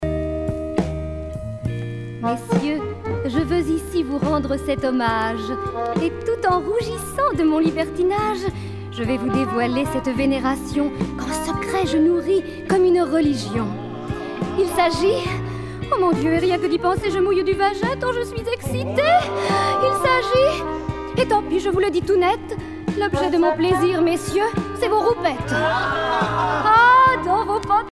débauche, paillardises
Pièce musicale éditée